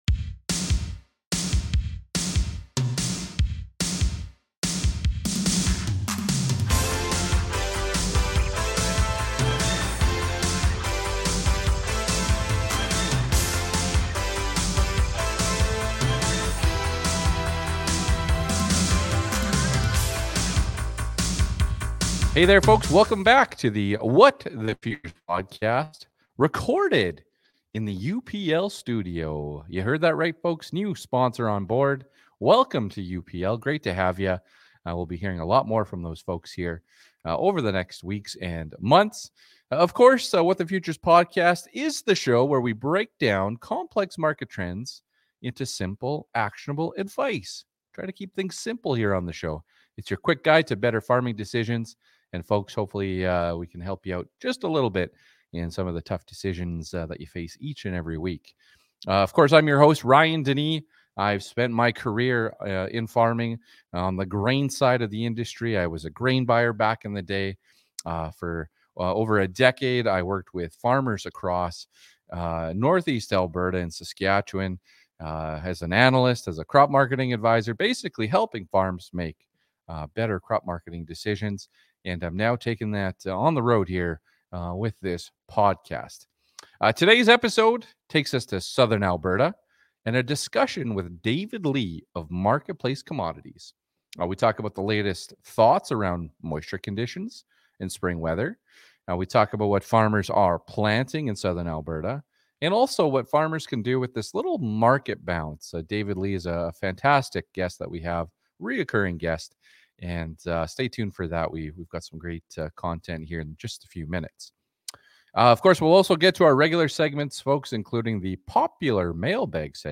Episode #19 was recorded in the UPL Studio!